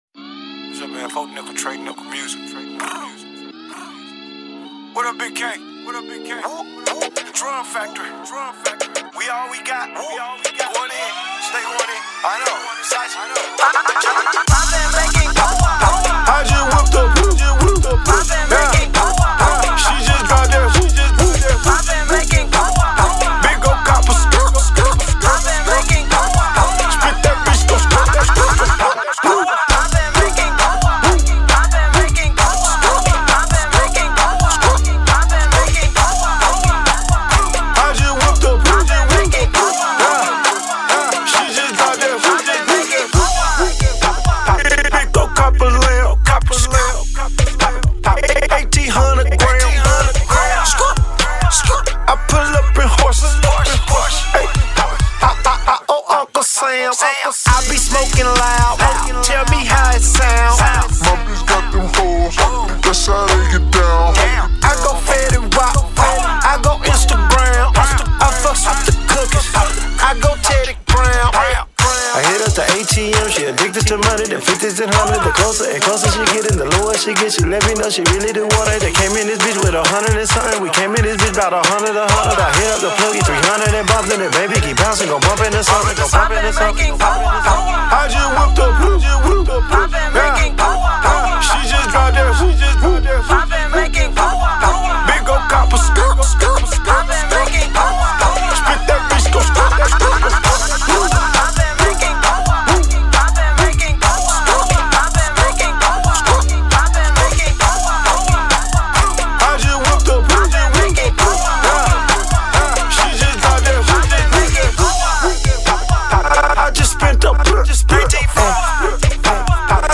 Hiphop
hott club banger